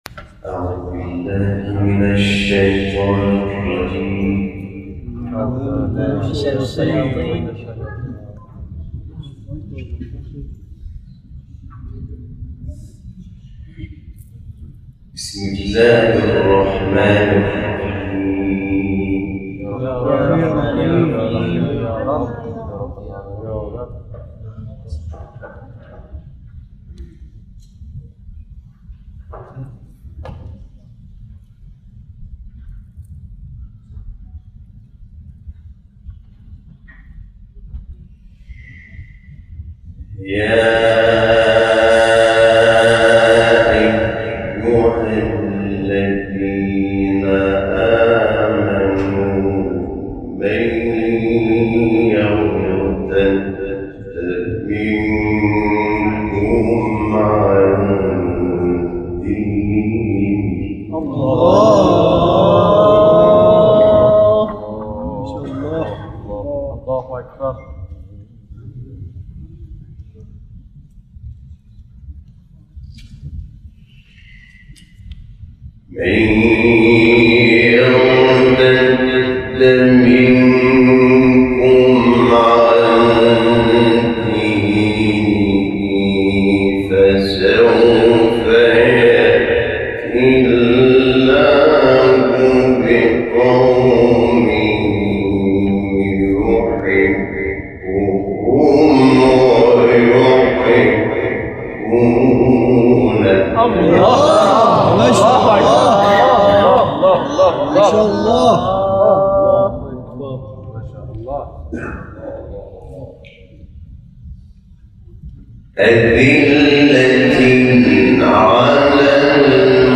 جدیدترین تلاوت
شب گذشته با حضور قاریان ممتاز و قرآن‌آموزان در مجتمع فرهنگی امام رضا(ع) برگزار شد.